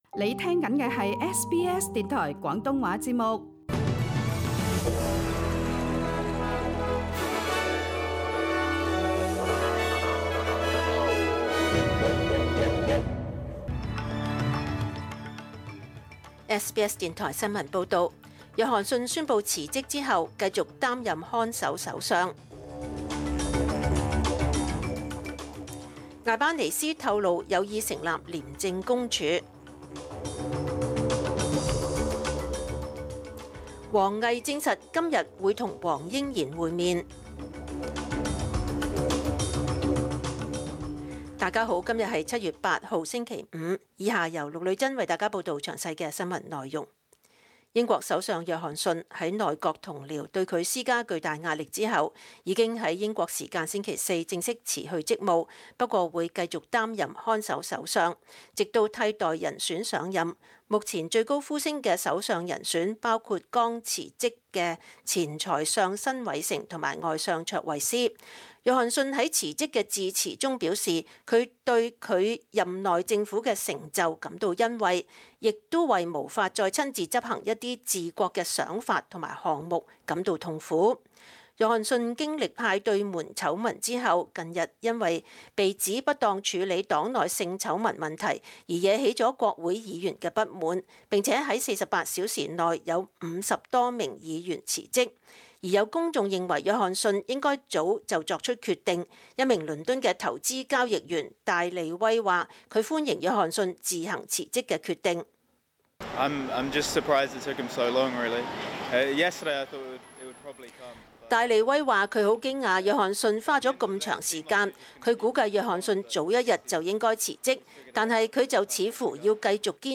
SBS 中文新闻 （7月8日）